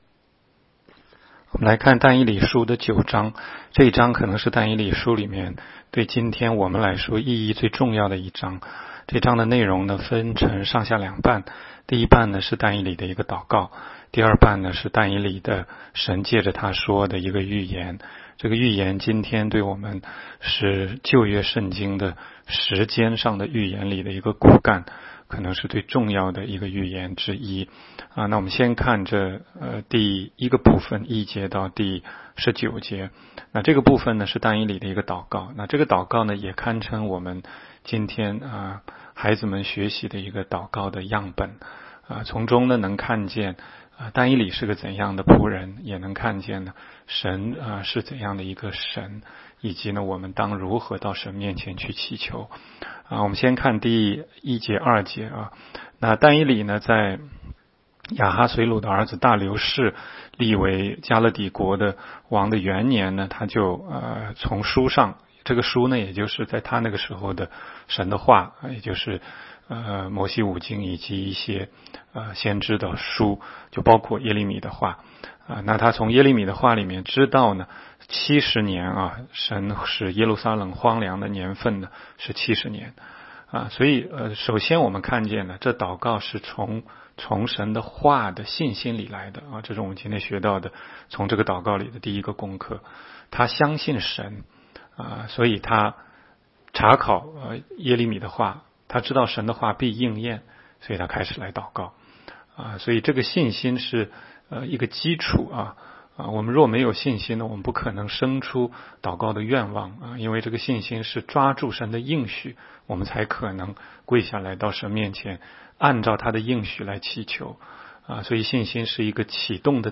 16街讲道录音 - 每日读经 -《但以理书》9章